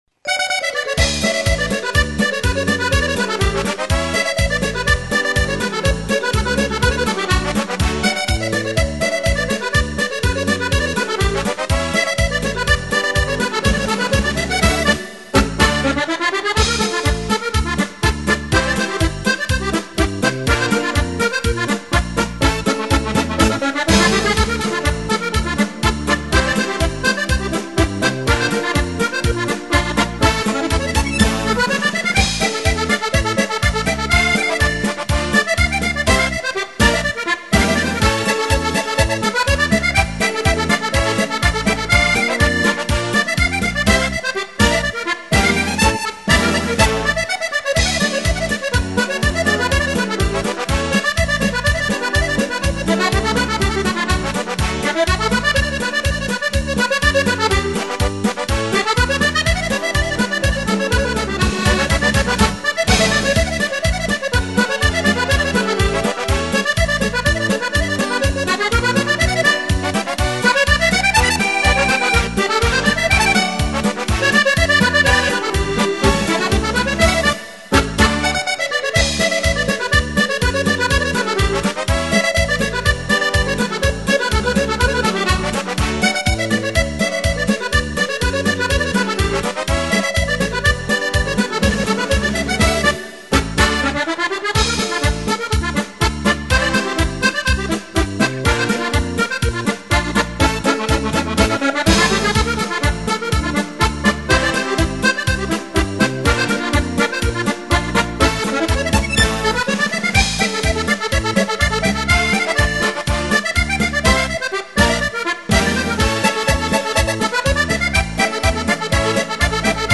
(PASO DOBLE)